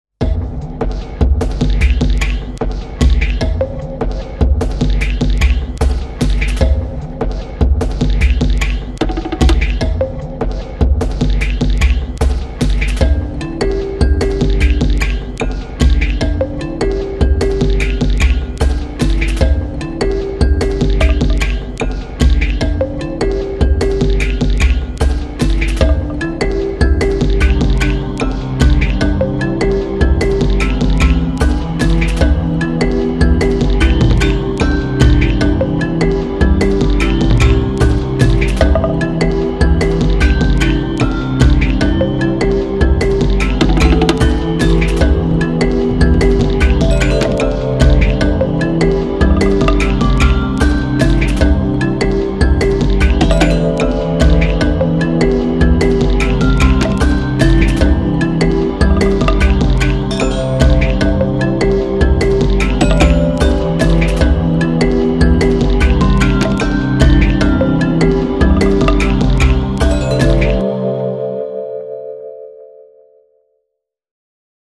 Largo [40-50] mefiance - orchestre symphonique - - -